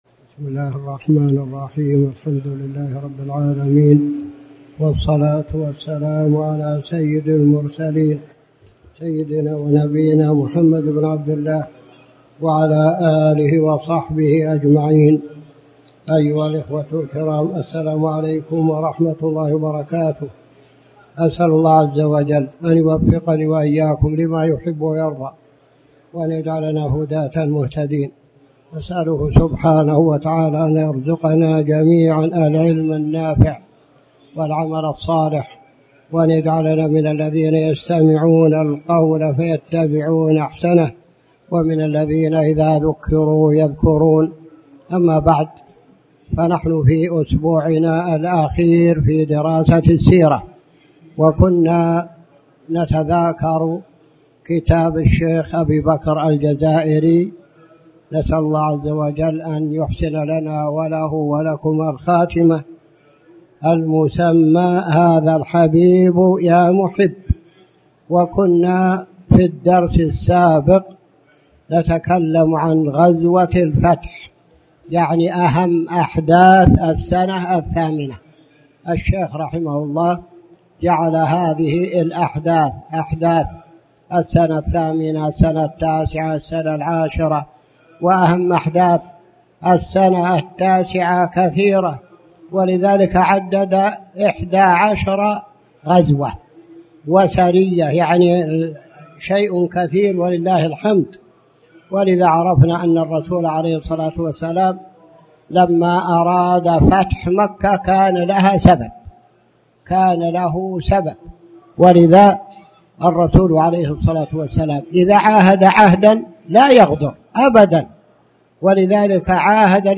تاريخ النشر ٢٤ محرم ١٤٣٩ هـ المكان: المسجد الحرام الشيخ